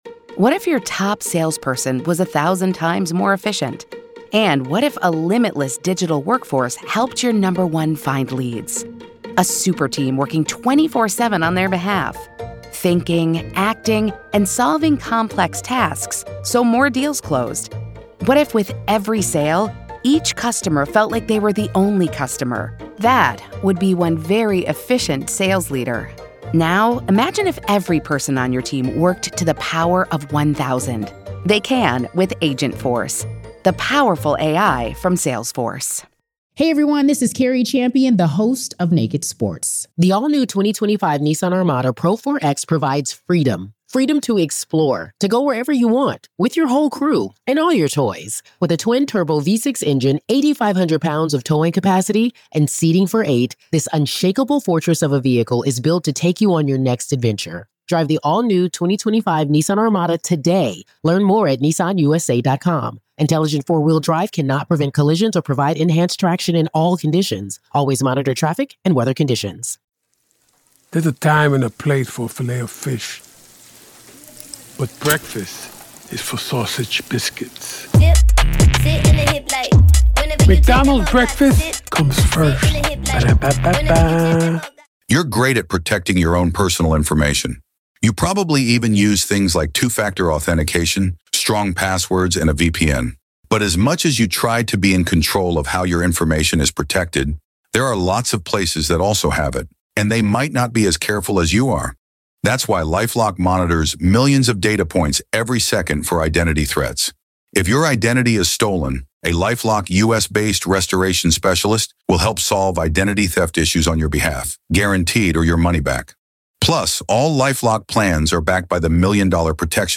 This conversation explores how someone who seemed like an ordinary, if eccentric, mom became convinced she was a goddess tasked with a deadly mission.